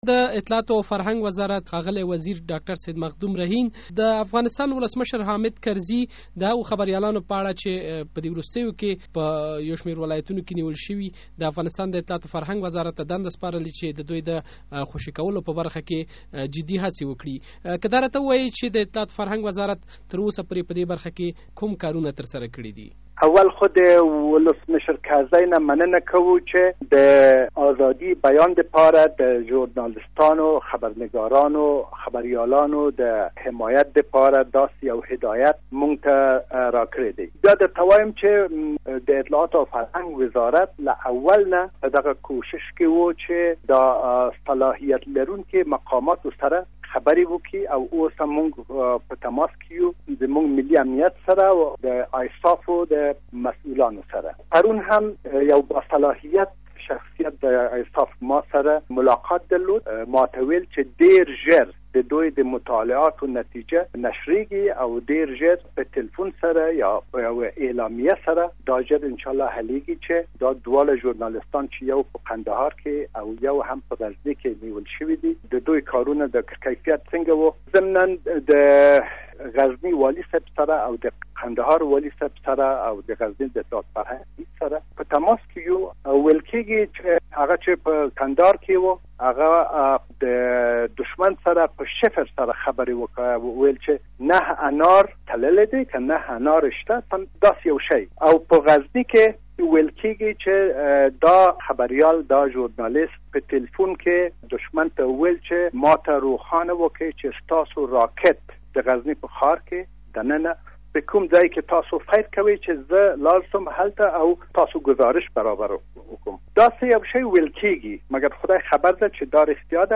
له سید مخدوم رهین سره مرکه